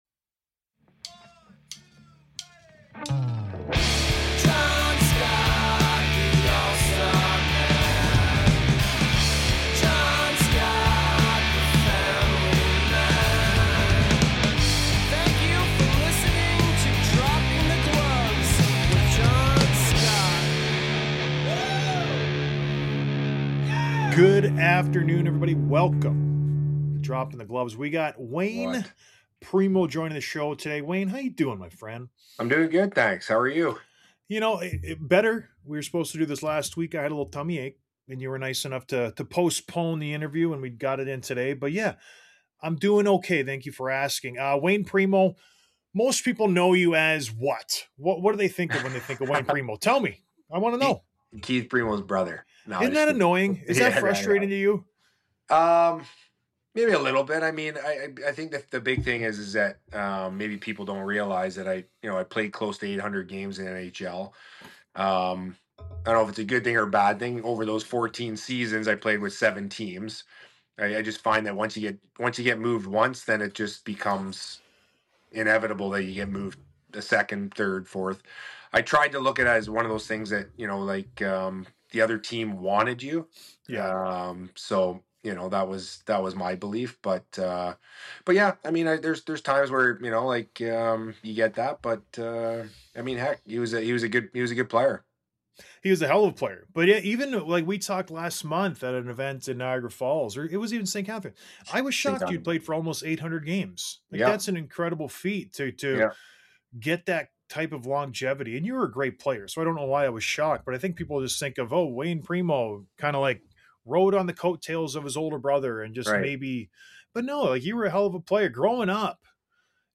Interview w/ Wayne Primeau